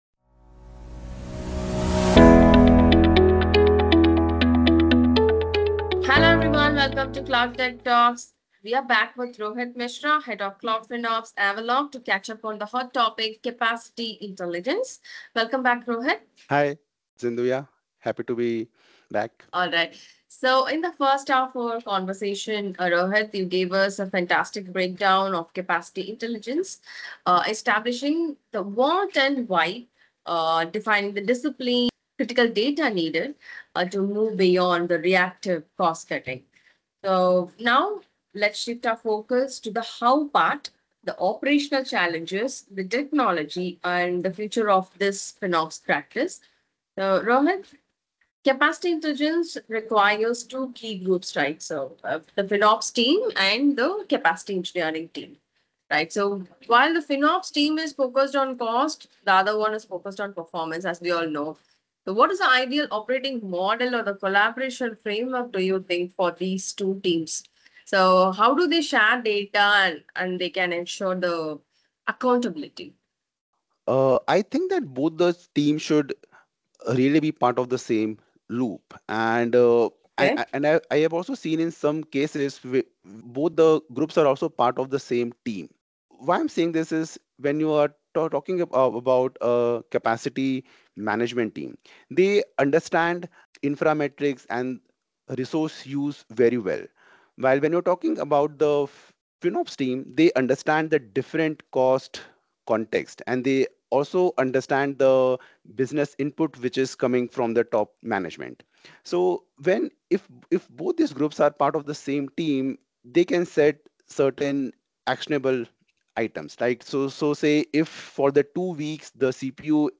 Join us as we delve into the latest developments and trends in cloud including topics such as cloud FinOps, migration strategies, and more. Each episode features expert guests from the tech industry, who share their insights, experiences, and practical tips to help you navigate the complexities of the cloud.